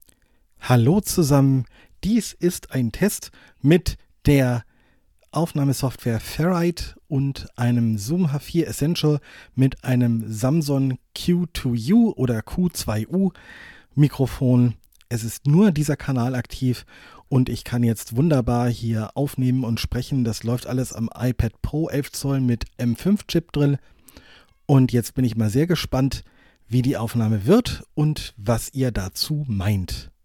Hier kommt mal eine kleine Testaufnahme eines potentiellen Audio-Recording-Setups. Im Einsatz ein iPad Pro 11 Zoll M5, ein Zoom H4Essential als Audio Interface, ein Samson Q2 U Mikrofon, per XLR angeschlossen, und die Software Ferrite als Aufnahmesoftware. Ein Tiefpassfilter, ein warmer Verstärker und leicht eingestellter Kompressor runden die Aufnahme ab.